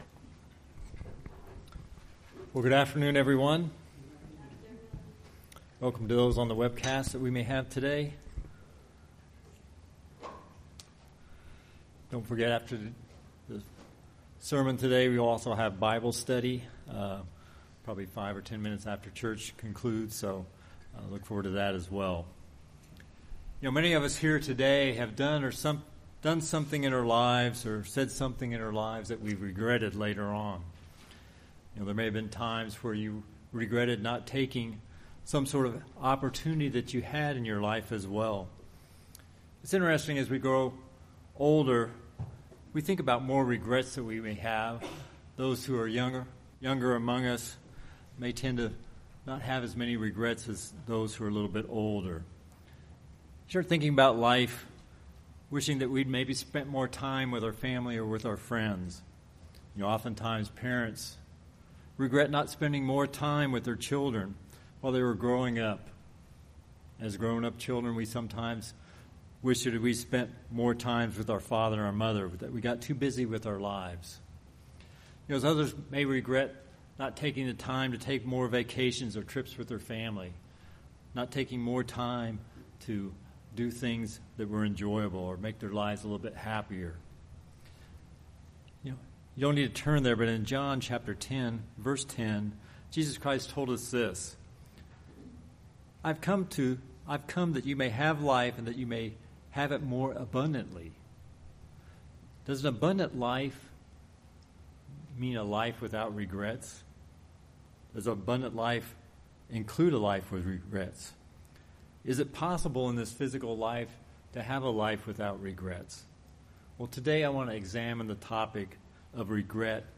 In this sermon we will examine the topic of regret and how we can leave regret behind.